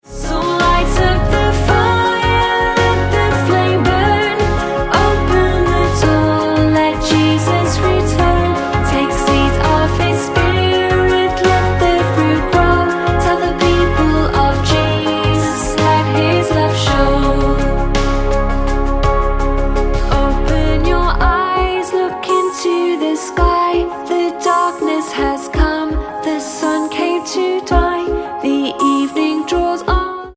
C#